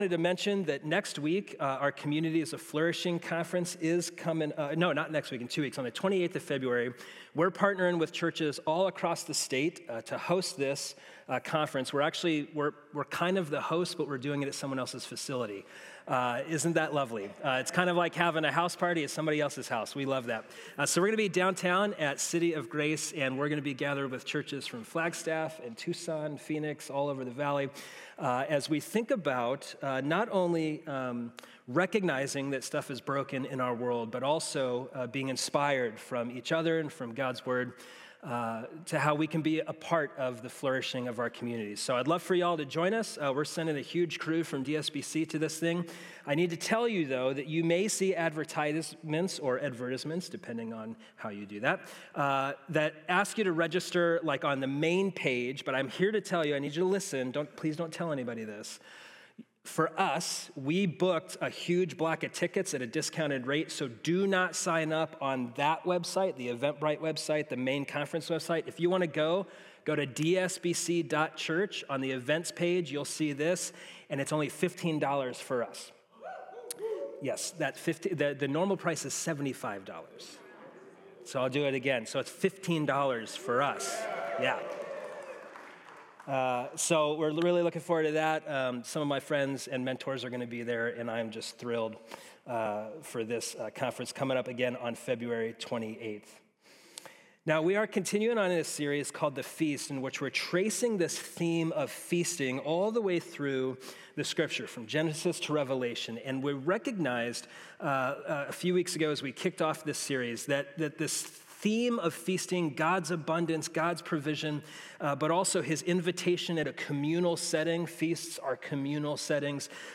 In The Feast series, this sermon explores Passover as one of Scripture’s central meals of remembrance, rooted in Exodus 12–14 and echoed through the biblical story from Genesis to Revelation.